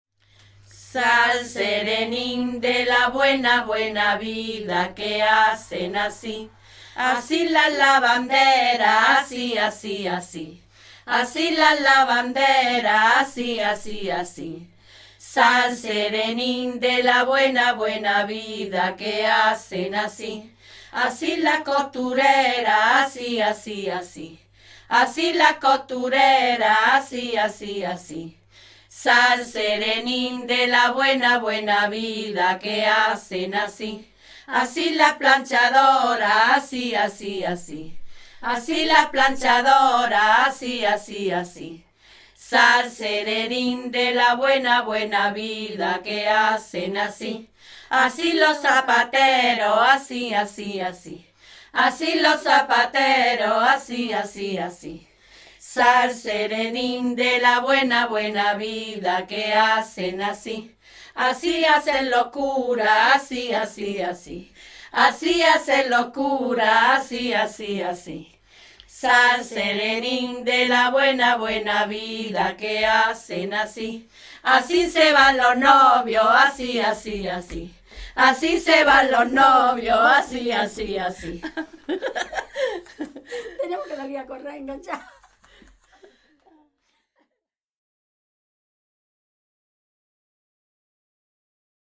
Registros relacionados: En: 3er Certamen de Narración Oral ; 4ª Semana de la Oralidad (jun.-oct. 2003).
Materia / geográfico / evento: Canciones de corro Icono con lupa
Secciones - Biblioteca de Voces - Cultura oral